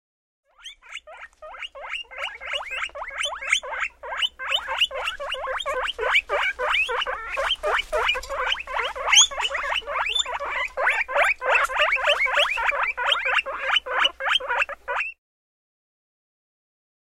Две морские свинки ведут беседу между собой